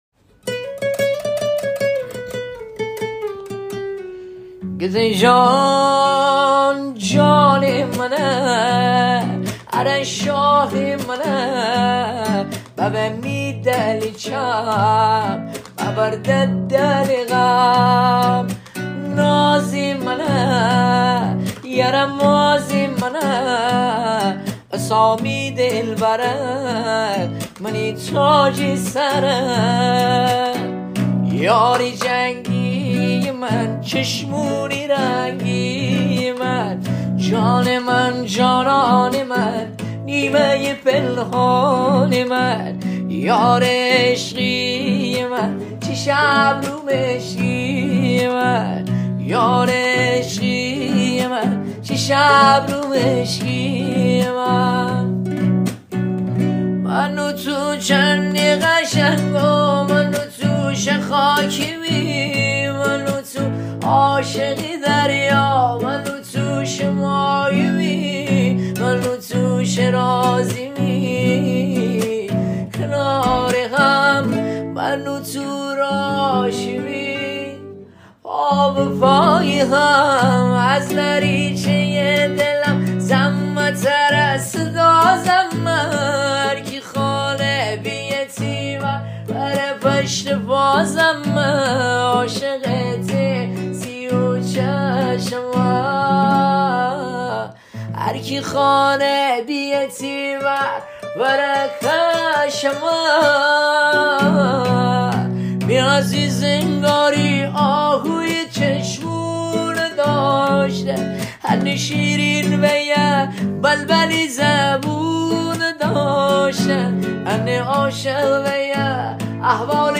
ریمیکس اهنگ مازندرانی
ریمیکس تند بیس دار